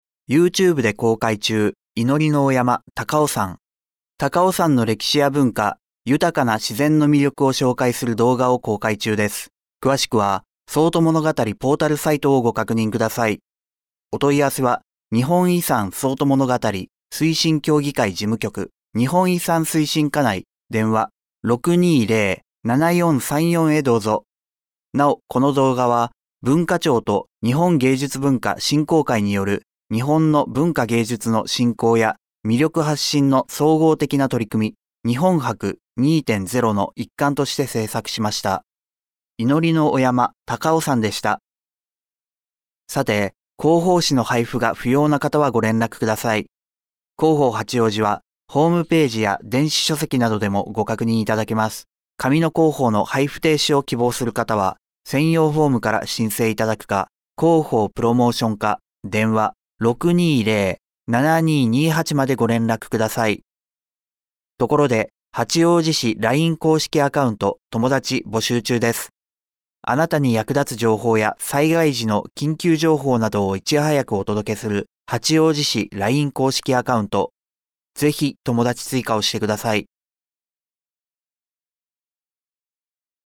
「声の広報」は、視覚障害がある方を対象に「広報はちおうじ」の記事を再編集し、音声にしたものです。